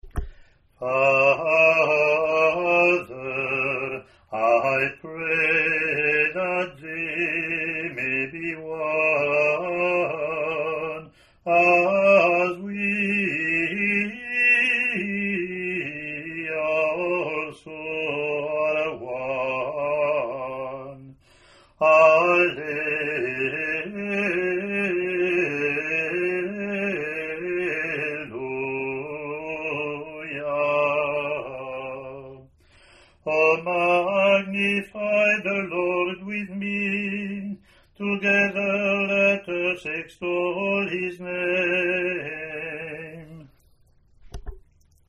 English antiphon – English verseLatin antiphon + verses )
ea07-communion-eng-pw.mp3